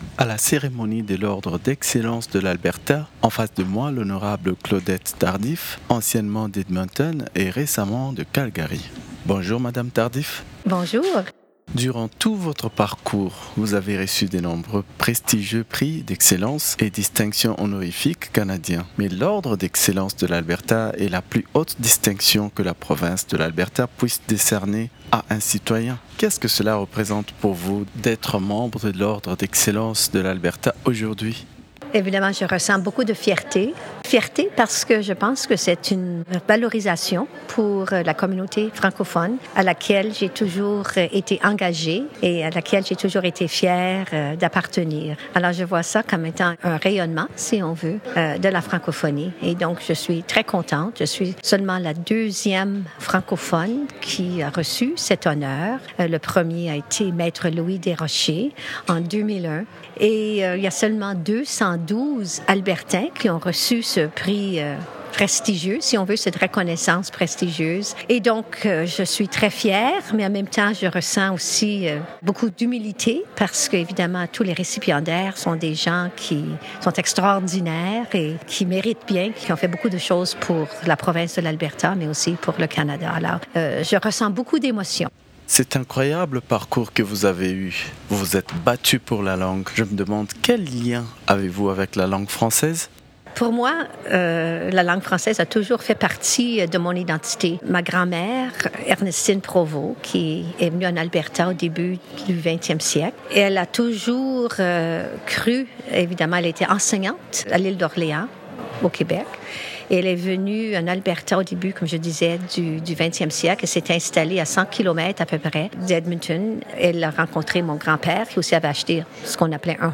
Entrevue-Claudette-Tardif-2.mp3